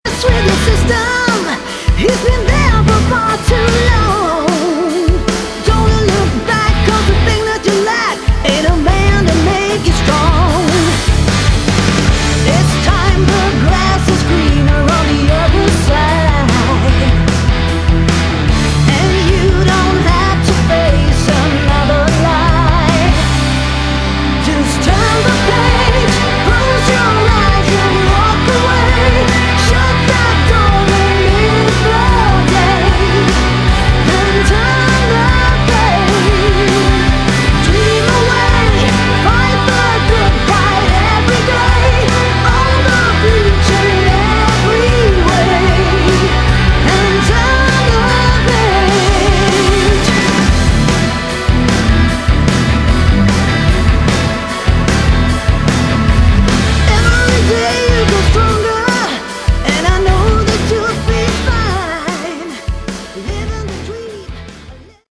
Category: Hard Rock
vocals
guitars
bass guitar
drums